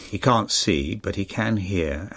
The negative can’t  is produced with the three elements stated before: 1. stress; 2. /ɑː/ vowel; 3.  /t/ not pronounced. The positive can is emphatic, and that’s why the speaker stresses it and uses the full vowel /kænt/.